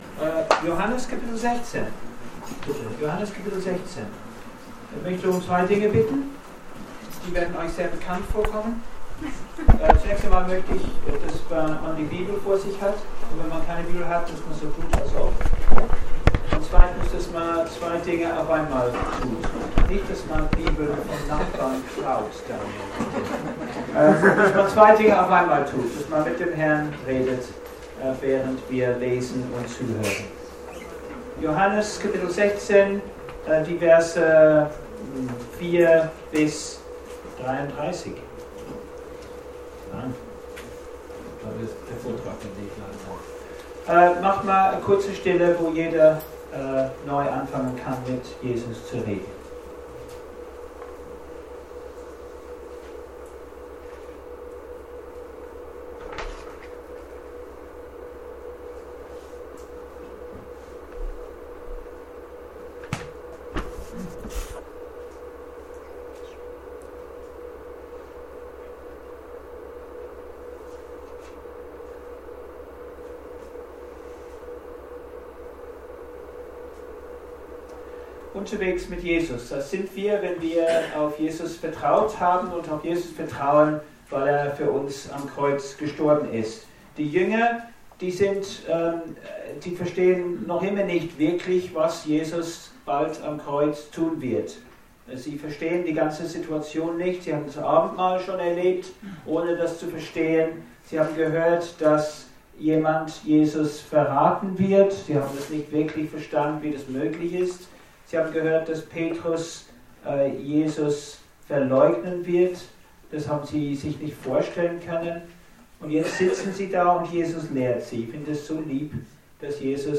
Gemeindefreizeit 2014
Predigtreihe